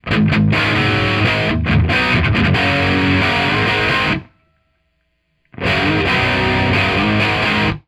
For all examples the guitar used is an SG with a Burstbucker 2 pickup in bridge position.
All mics were placed directly in front of the speakers roughly focused between the center cone and the outer edge at a 45deg angle.
No settings were changed on the amp or guitar during the recordings and no processing or eq was applied to the tracks.
Groove Tubes GT67 tube powered large diaphragm street price $700
Large Condenser-GT67
Large-Condenser-GT67.wav